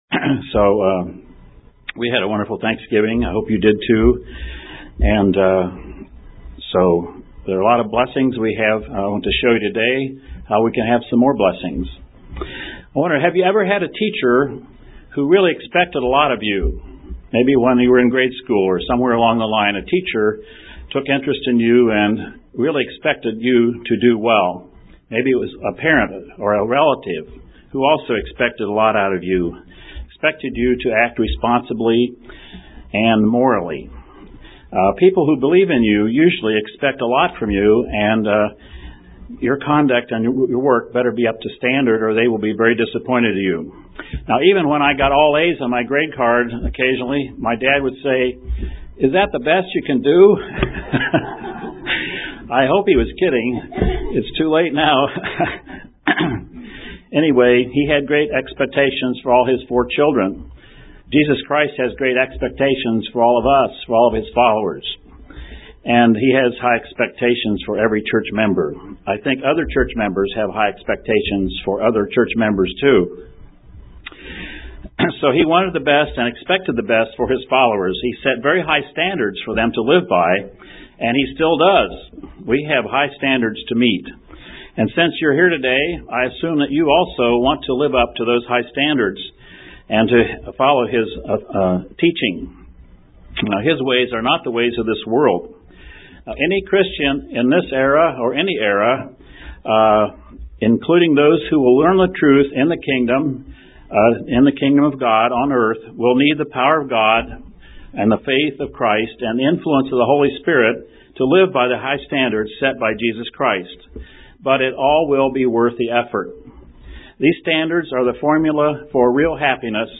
How important it is that we learn about Christ's lessons and teachings that are given in the Beatitudes (Sermon on the Mount). (Broadcast from Cincinnati)
(Broadcast from Cincinnati) UCG Sermon Studying the bible?